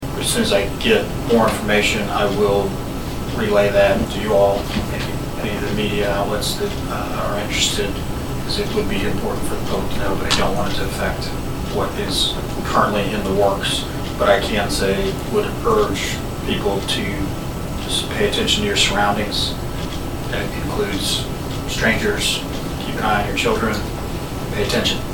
Saline County Prosecuting Attorney Tim Thompson was at the meeting of the county commission on Thursday, August 8, and said he is limited on what information he can convey at this time because of an ongoing investigation, but he urges area residents to pay attention to their surroundings.